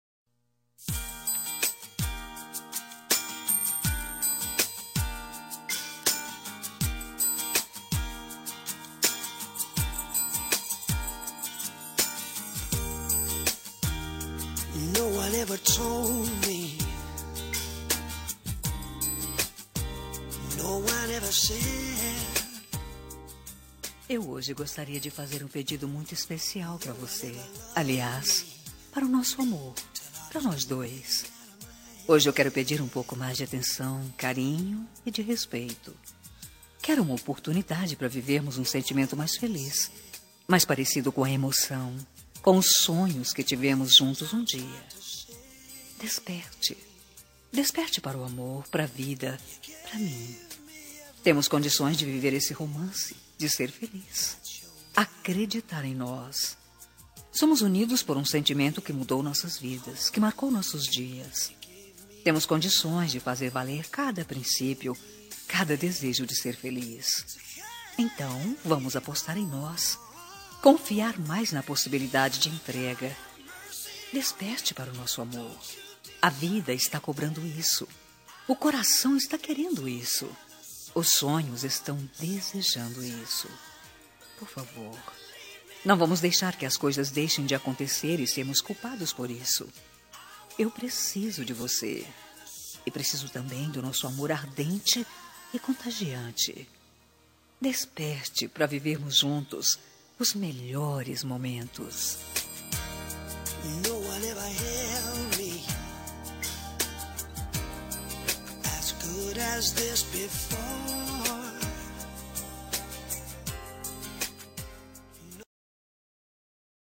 Telemensagem Relacionamento Crise – Voz Feminina – Cód: 5438